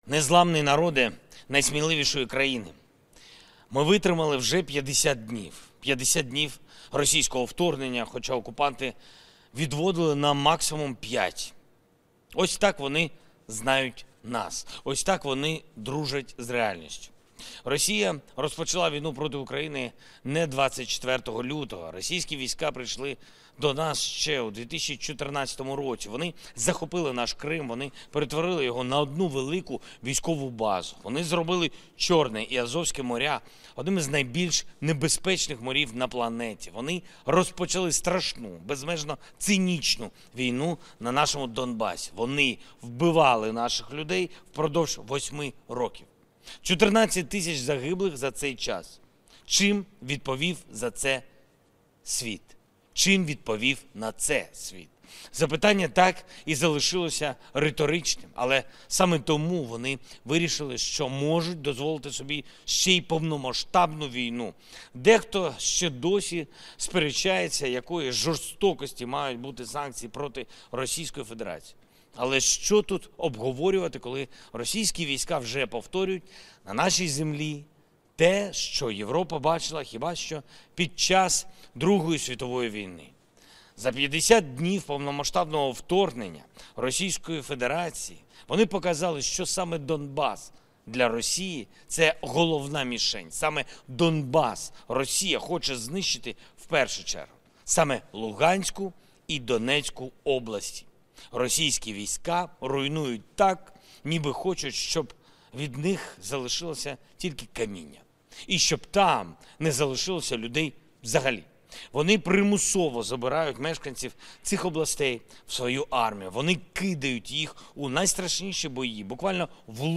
52 день війни. Звернення Президента України
Володимир Зеленський звернувся до українського народу на 52 день війни в країні.